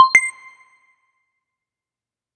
Game Sound Wrong
FX game Incorrect sound sound-effects video-game wrong sound effect free sound royalty free Sound Effects